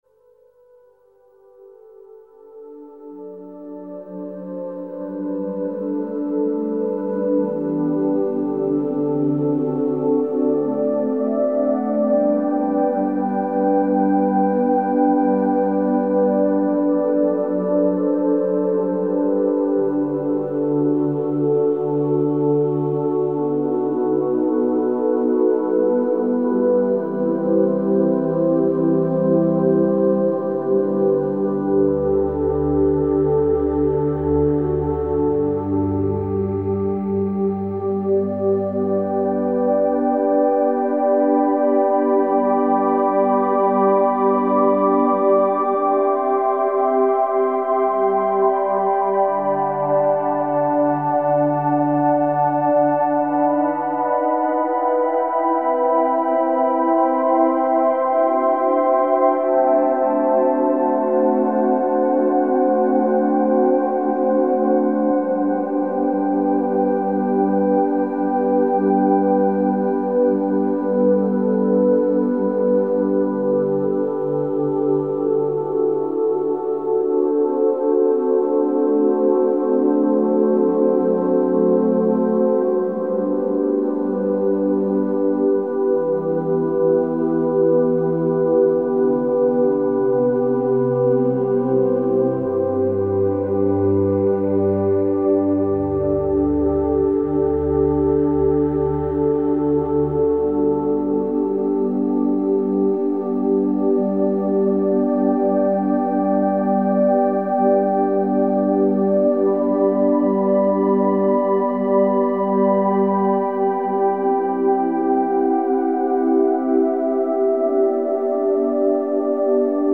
Wenn ich dazu einen bestimmten Musiktitel in Dauerschleife hörte, den ich heute nur noch als sanfte Chakra-Musik erinnere, begann sich etwas in mir zu wandeln. Das Glitzern des Lamettas, das im Licht leise schimmerte, verband sich mit den zarten Klängen zu etwas beinahe Magischem.